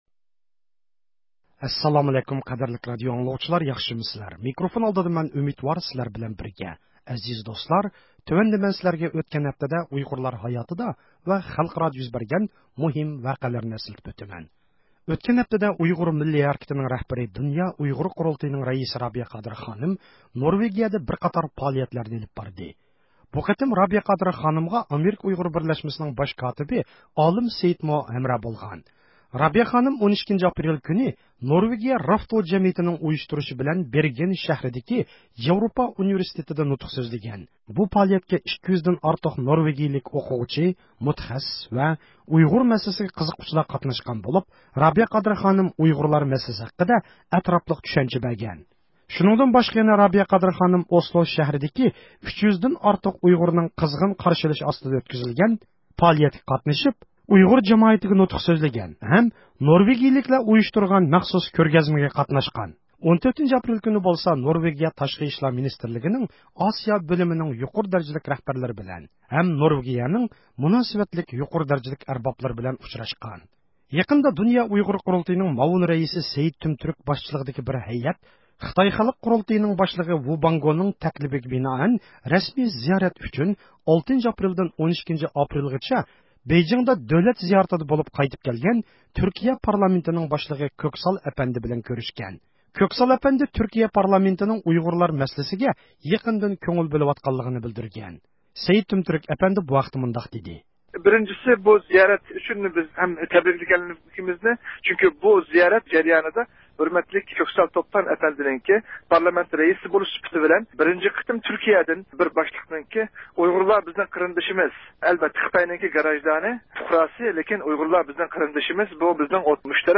ھەپتىلىك خەۋەرلەر (13 – ئاپرېلدىن 18 – ئاپرېلغىچە) – ئۇيغۇر مىللى ھەركىتى